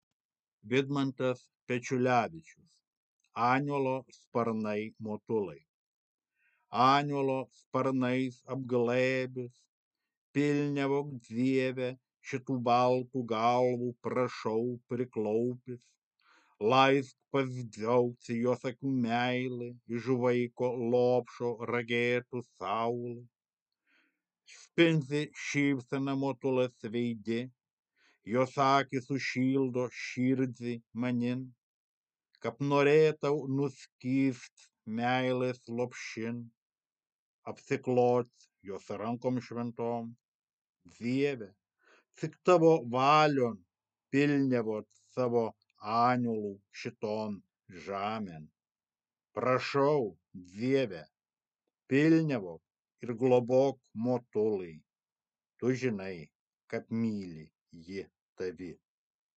Įgarsinimai lietuvių kalba
Dzūkiška tarmė
dzukiska-tarme-esu.mp3